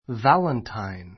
valentine vǽləntain ヴァ れンタイン 名詞 ❶ バレンタインカード, バレンタインのプレゼント 参考 2月14日の聖バレンタインの日 （ （Saint） Valentine's Day ） に愛を込 こ めて, 友人, 家族, 先生, 恋人 こいびと などに送るカード （ valentine card ） や菓子 かし ・花など.